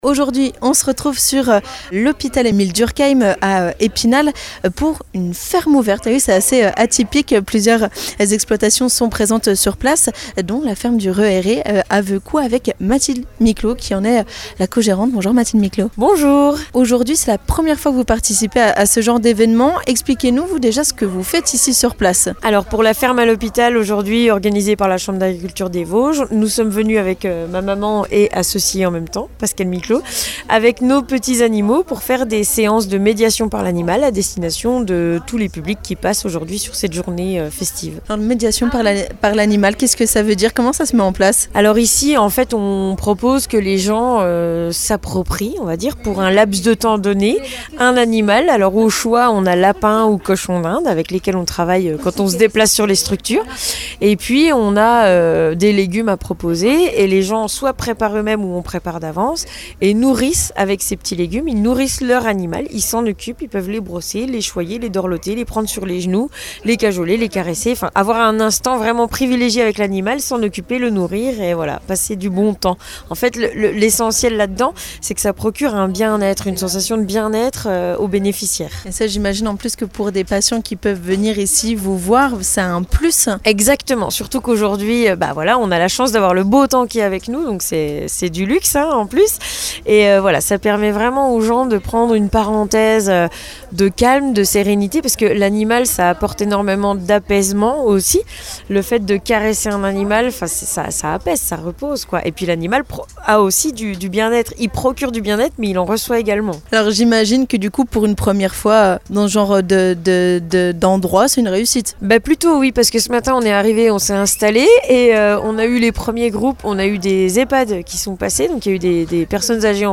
Ce jeudi, la ferme s'est invitée au centre hospitalier Emile Durkheim d'Epinal ! Une journée d'animations autour de la médiation animale, ou encore d'ateliers ludiques.